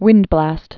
(wĭndblăst)